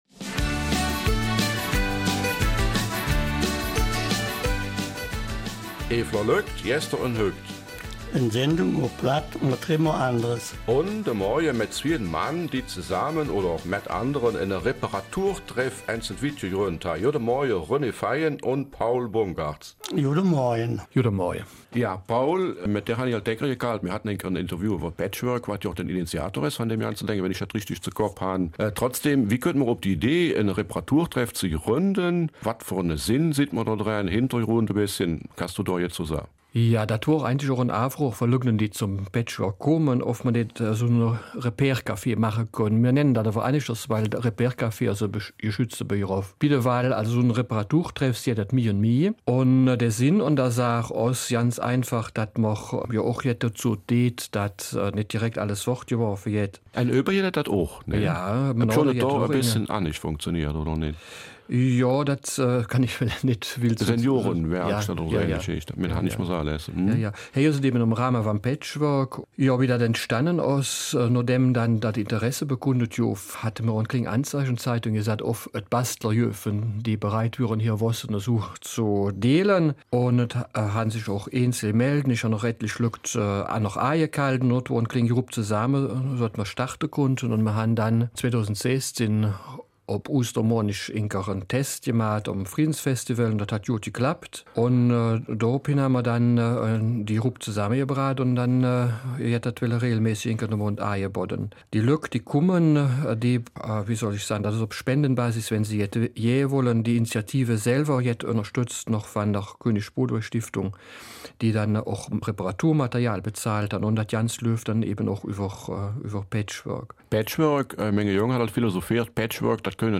Eifeler Mundart: Reparatur-Treff in St.Vith